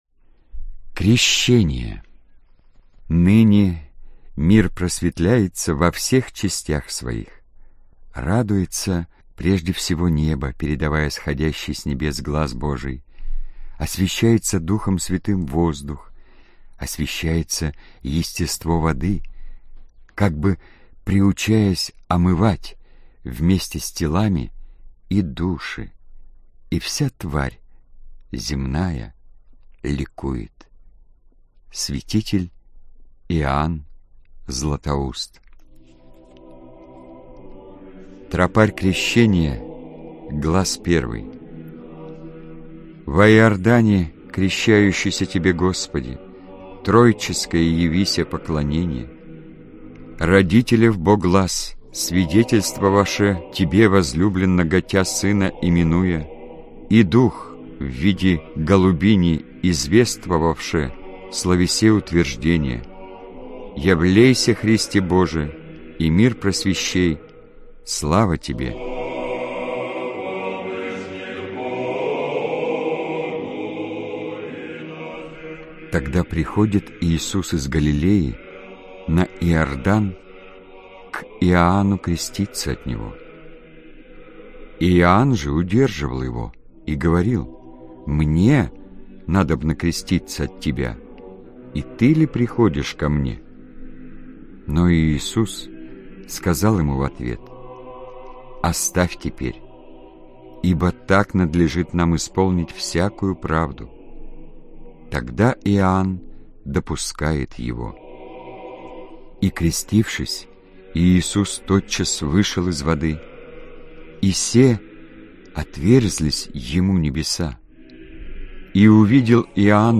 Аудиокнига Свет рождественской звезды | Библиотека аудиокниг